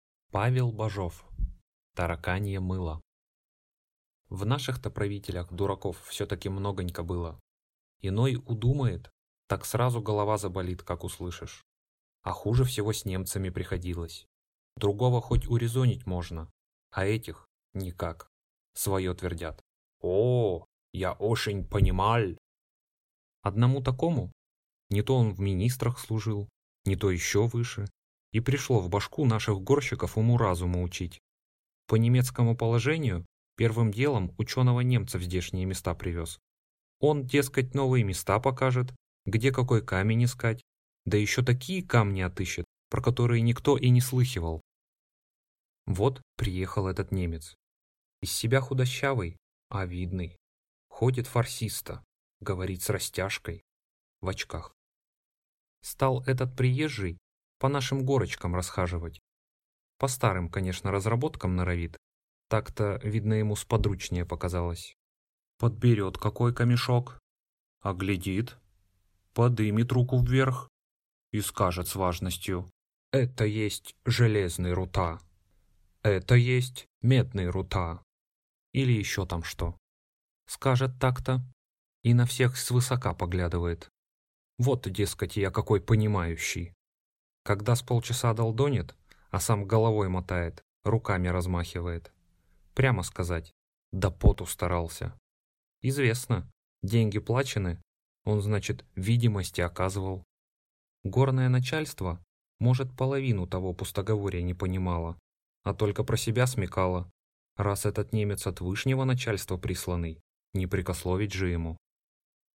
Аудиокнига Тараканье мыло | Библиотека аудиокниг